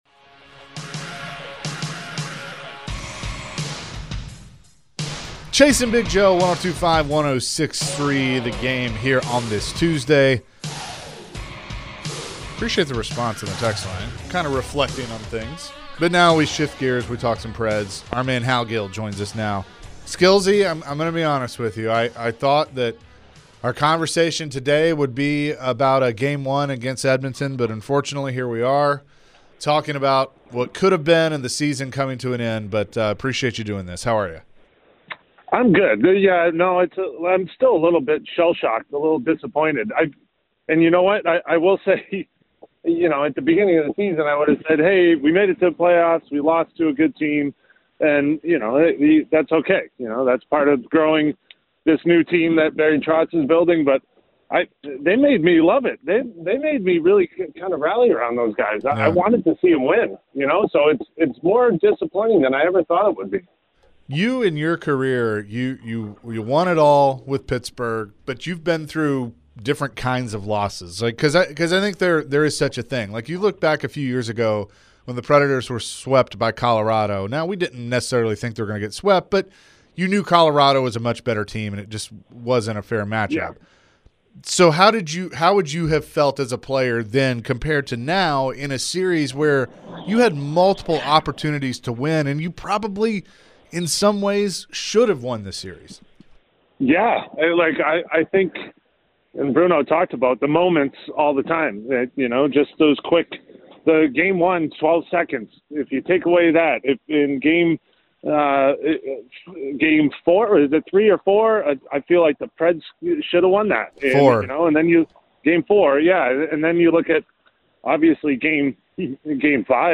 Nashville Predators radio and TV analyst Hal Gill joined the show and shared his thoughts on the Nashville Predators season and the core of the roster. What will be the next steps for the Nashville Predators this upcoming off-season?